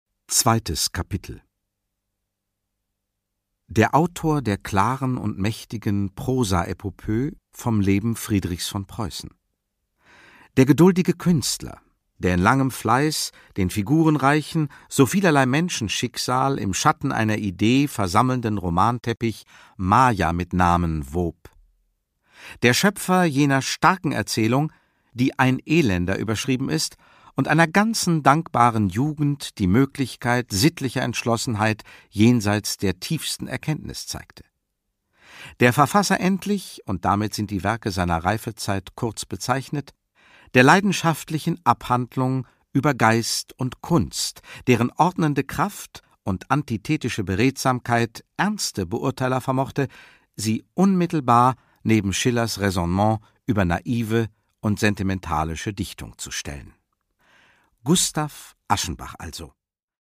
Thomas Mann: Der Tod in Venedig (Ungekürzte Lesung)
Produkttyp: Hörbuch-Download
Gelesen von: Gerd Wameling